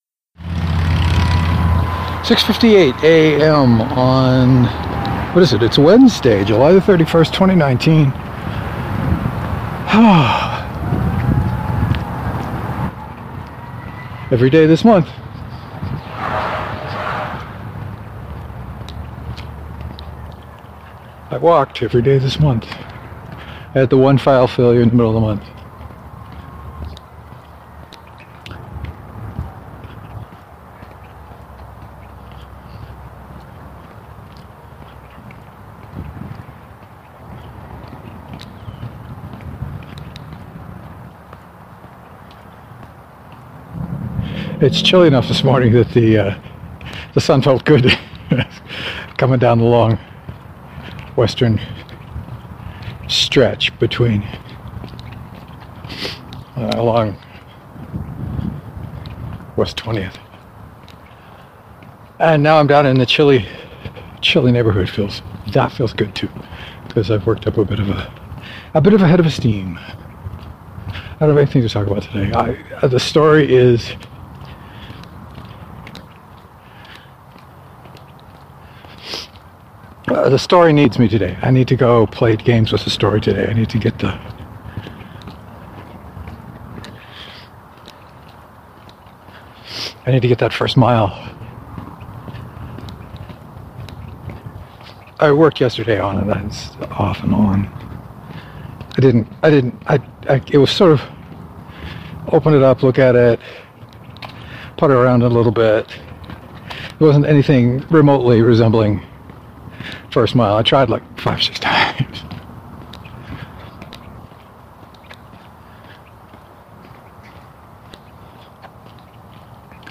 Production note: I seem to have been mumbling a lot.